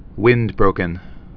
(wĭndbrōkən)